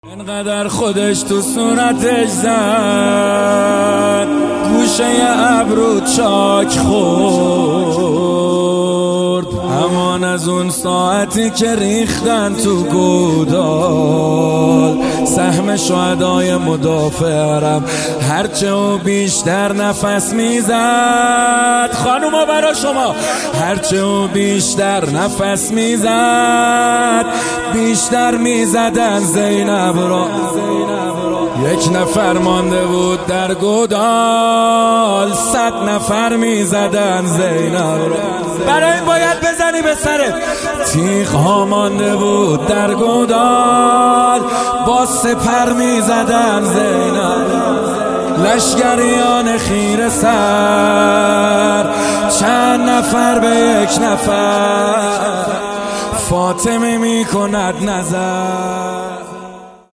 مداحی کربلایی محمد حسین حدادیان | محرم الحرام 1399 هیئت رزمندگان اسلام